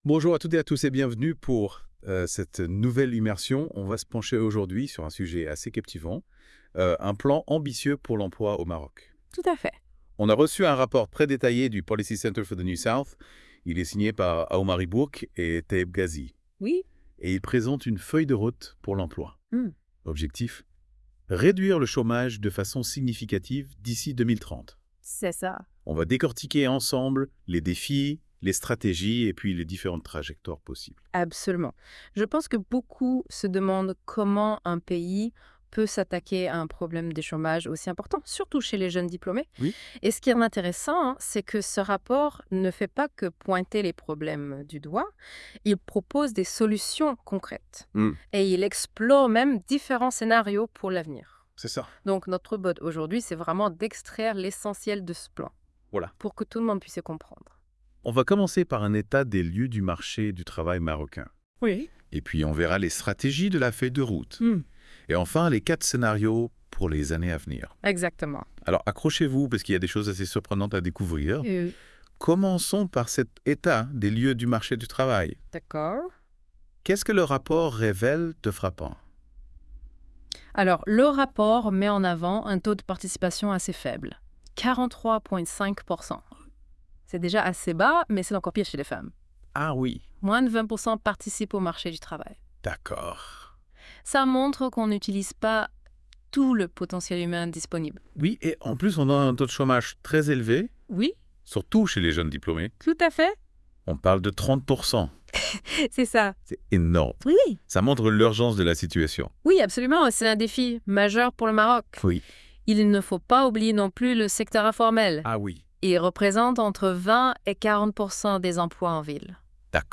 Débat (43.18 Mo) 1.